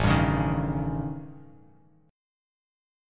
A7b5 chord